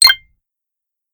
Categories: Games